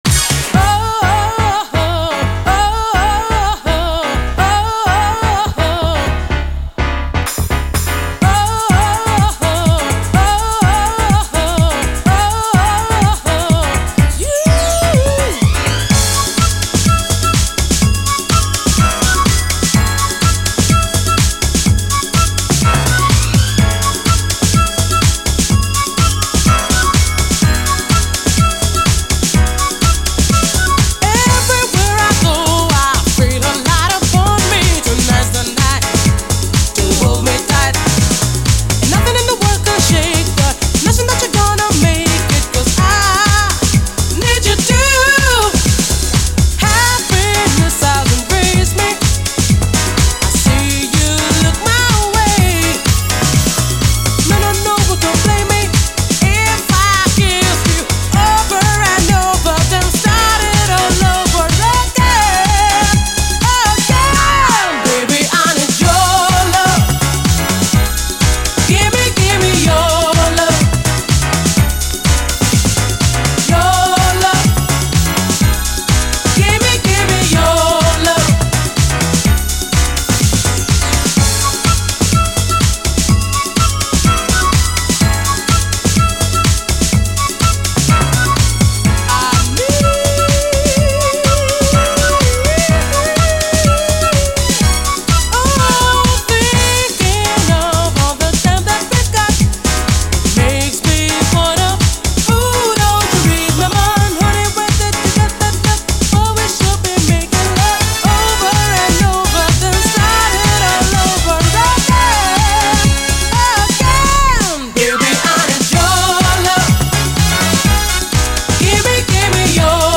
DISCO, DANCE
イタリア産アップリフティング・ヴォーカル・ハウス！